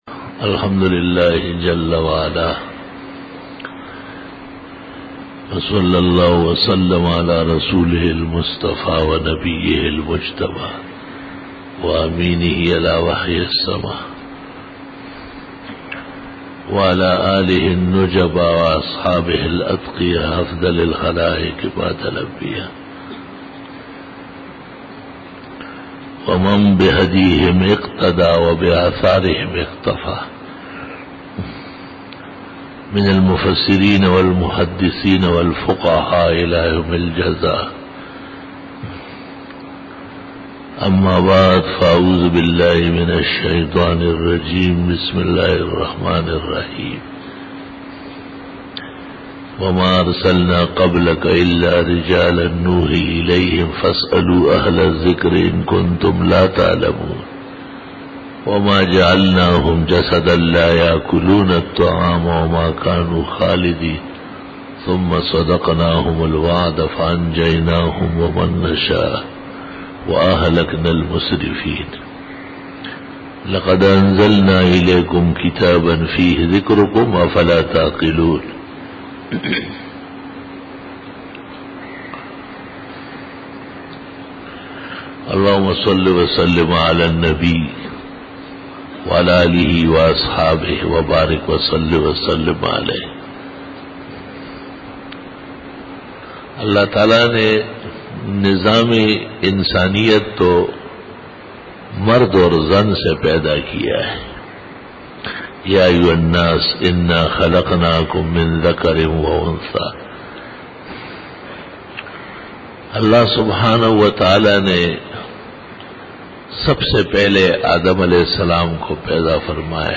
11_BAYAN E JUMA TUL MUBARAK 14-MARCH-2014
Khitab-e-Jummah 2014